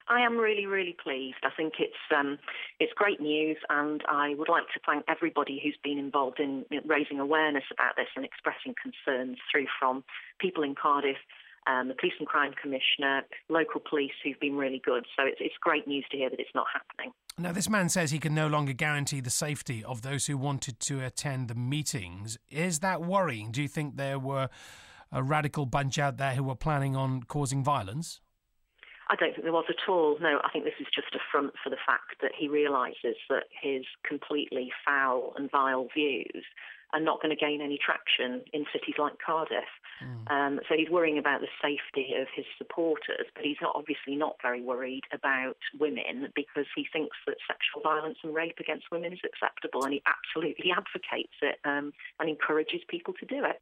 Jo Stevens, Labour MP for Cardiff Central, gave Jason Mohammad her reaction to the news.